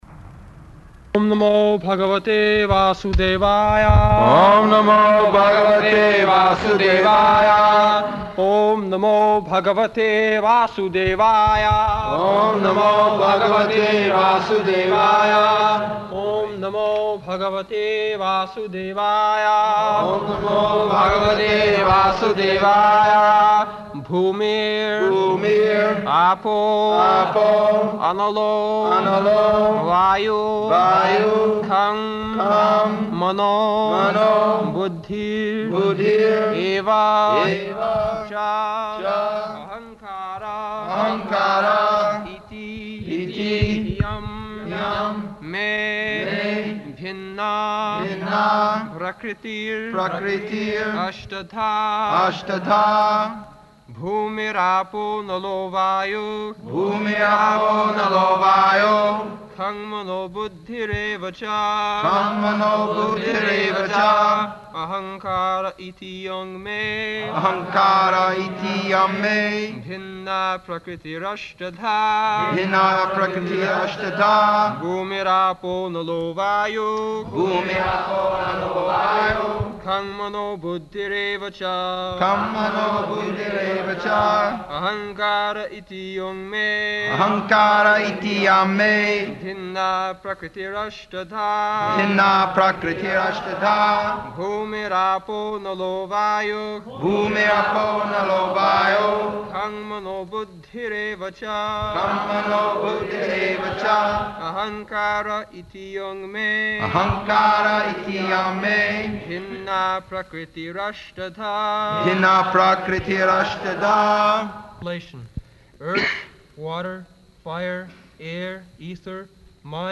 August 10th 1974 Location: Vṛndāvana Audio file
[devotees repeat] [leads chanting of verse, etc.]